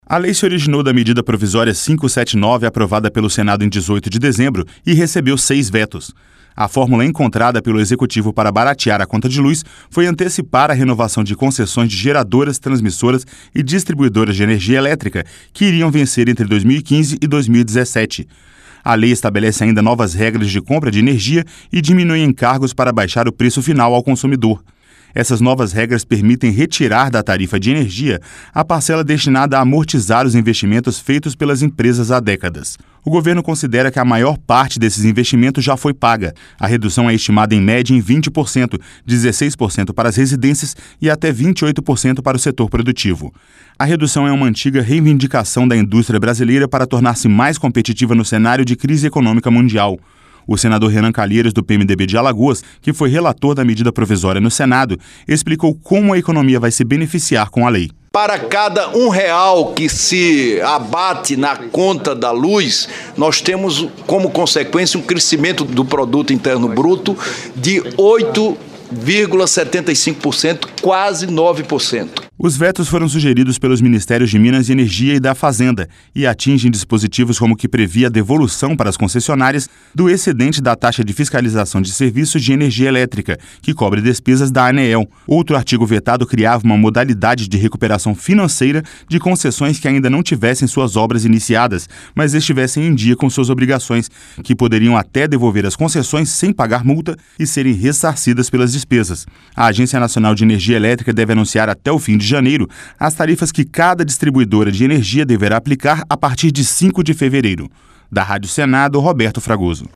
O senador Renan Calheiros, do PMDB de Alagoas, que foi relator da medida provisória no Senado, explicou como a economia vai se beneficiar com a lei.